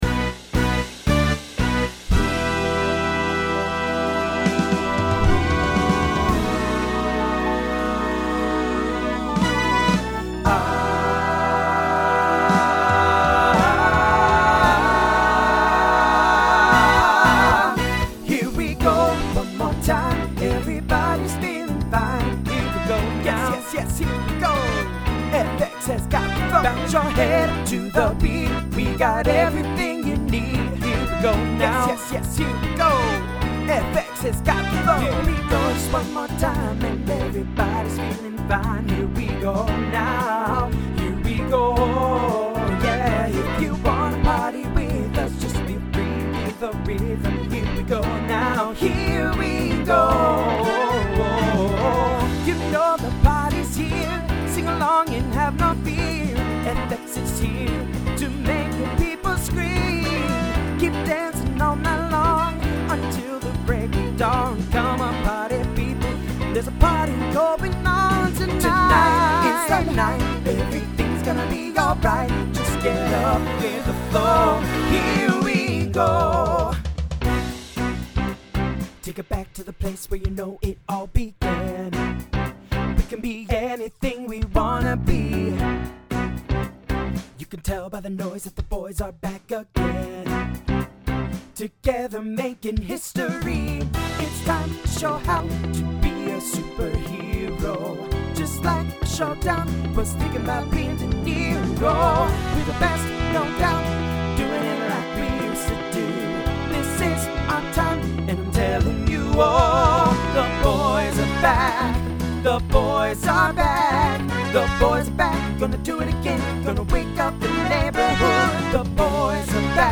Genre Broadway/Film , Pop/Dance
Voicing TTB